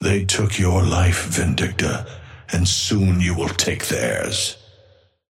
Amber Hand voice line - They took your life, Vindicta, and soon you will take theirs.
Patron_male_ally_hornet_start_05.mp3